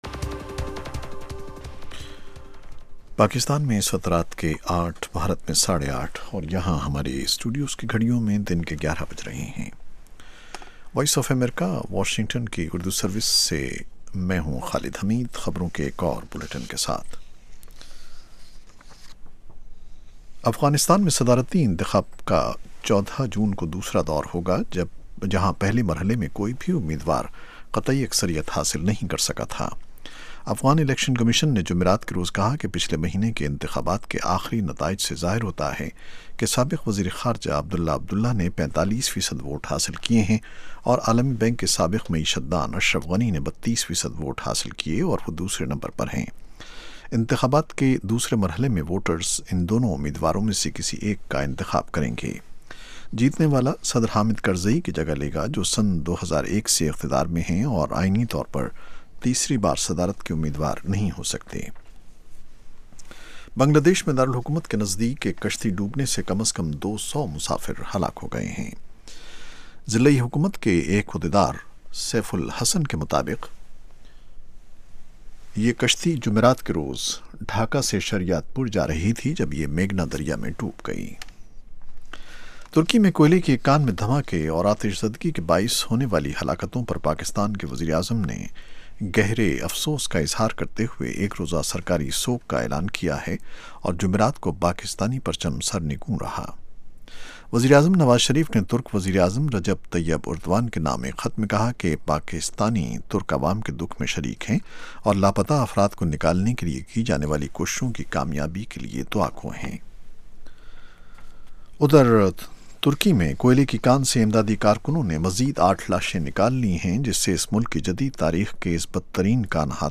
In The News: 8:00PM PST ایک گھنٹے دورانیے کے اس پروگرام میں خبروں کے علاوہ مہمان تجزیہ کار دن کی اہم خبروں کا تفصیل سے جائزہ لیتے ہیں اور ساتھ ہی ساتھ سننے والوں کے تبصرے اور تاثرات بذریعہ ٹیلی فون پیش کیے جاتے ہیں۔